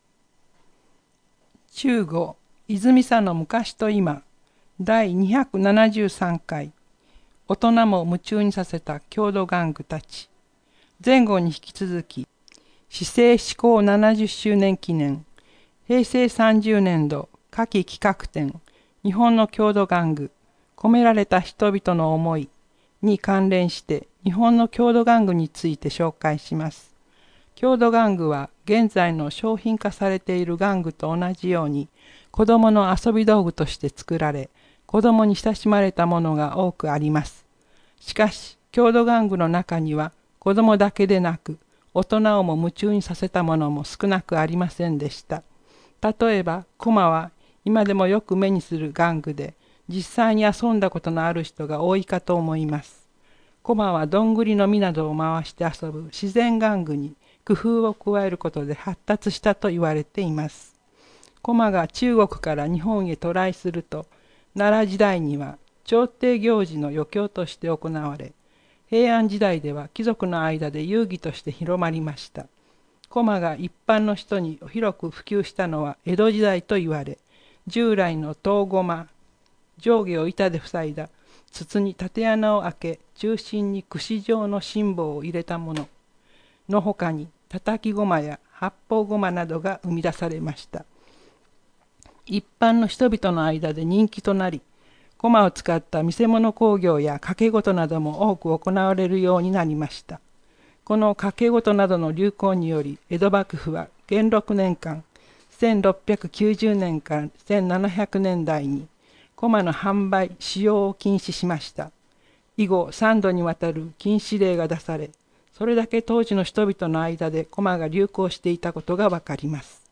このページでは、視覚障害をお持ちの方のために泉佐野市社会福祉協議会「声のボランティア」のみなさんが朗読した広報の音声ファイルをダウンロードできます。